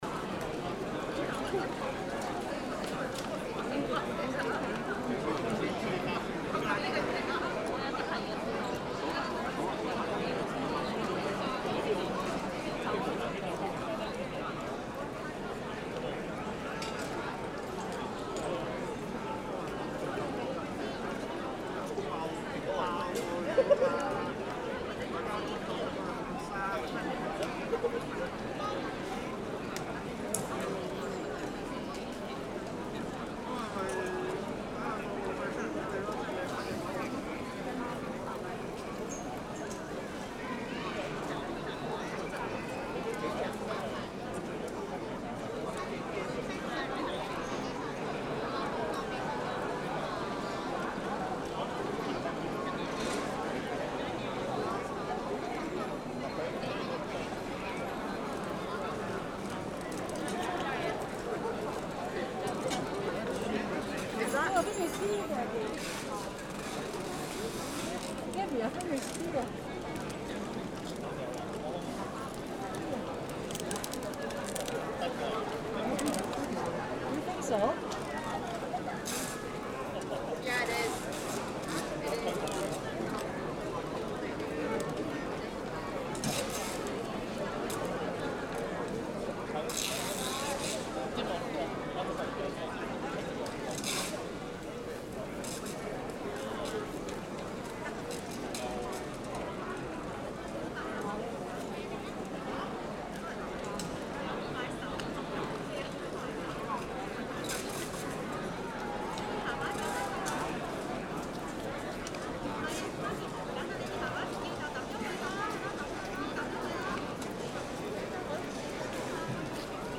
錄音位置於一號客運大樓F行，能夠聽到等候隊伍的對話、小孩玩樂的聲音和機場廣播。
The recording is located in Aisle F of Terminal 1, where you can hear the conversations of the queue, the sounds of children playing and the airport announcements.